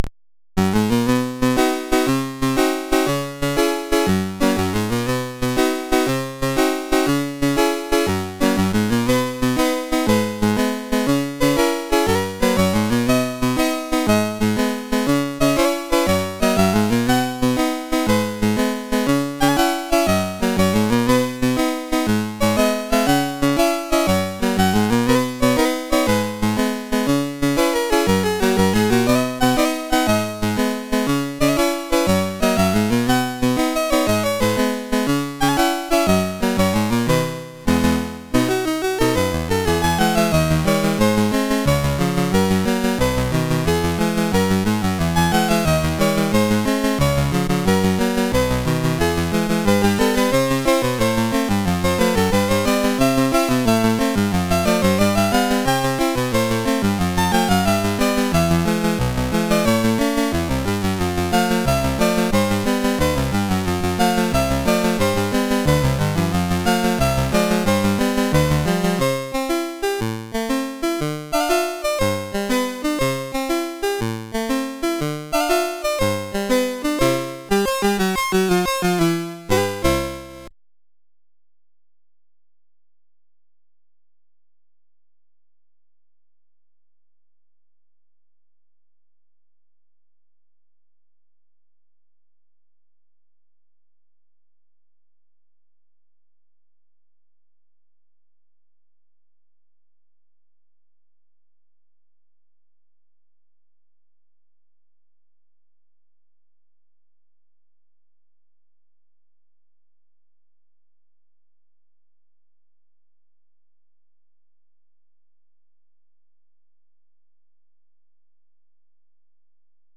Commodore SID Music File
1 channel